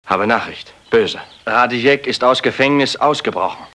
Sound file of German dubbing actor (59 Kb)